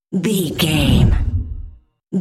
Deep whoosh pass by
Sound Effects
Atonal
dark
whoosh